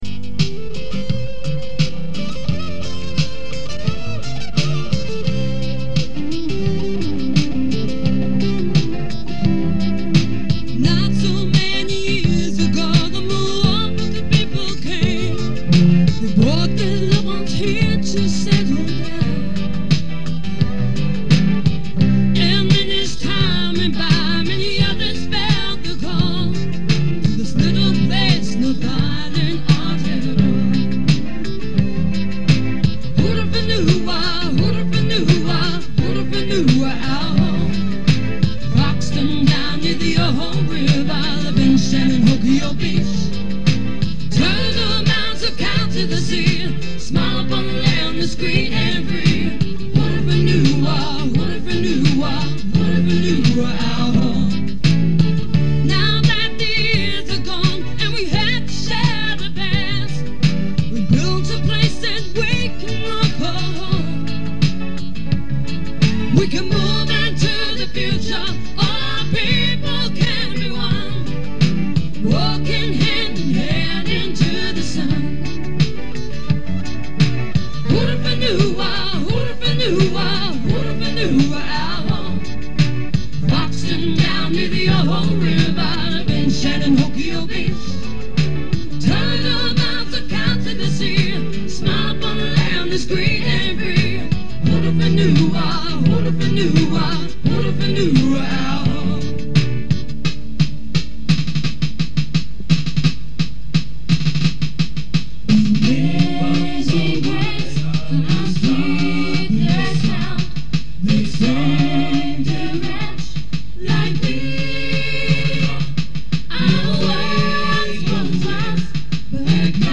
Presumably used at some promotion.